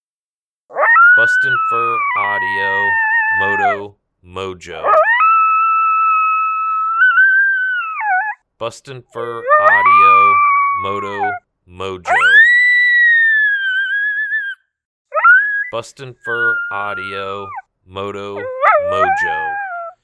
Juvenile Male Coyote inviting howl, great stand starter howl.